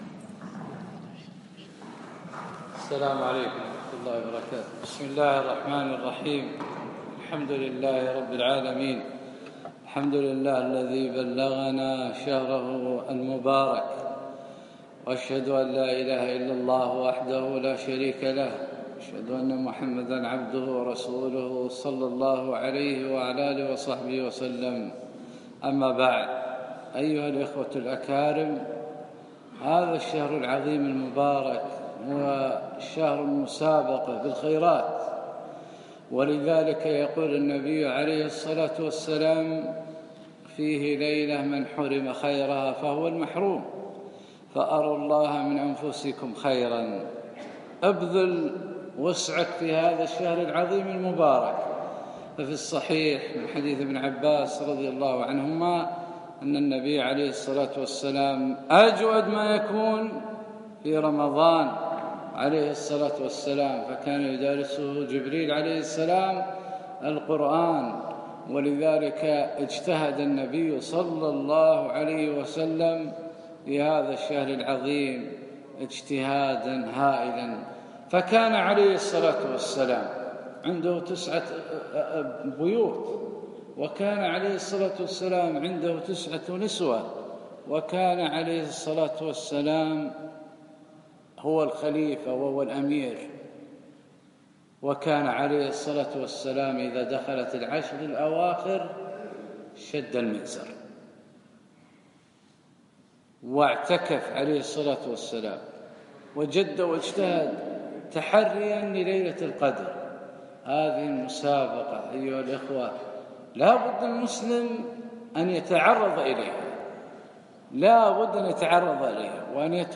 الخميس 6 رمضان 1437 الموافق 1 6 2017 بمسجد سعد بن عبادة خيطان